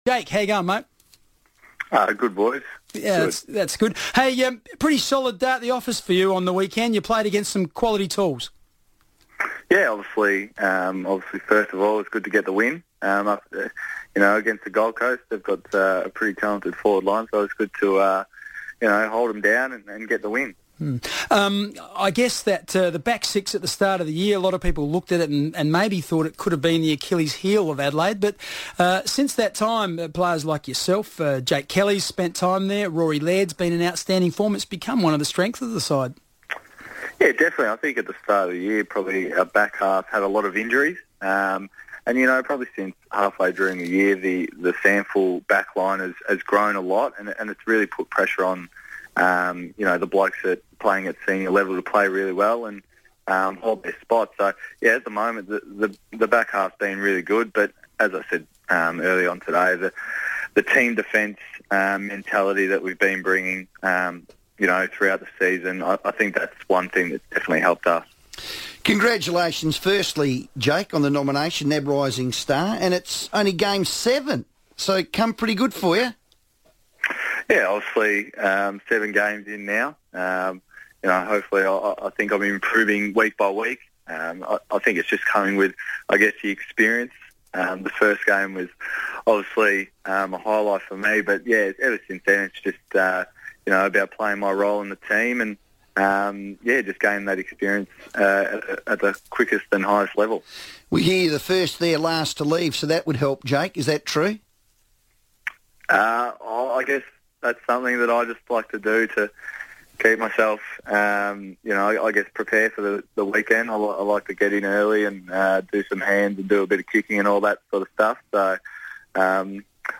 Rising Star nominee Jake Lever spoke on the FIVEaa Sports Show ahead of Adelaide's clash with the Swans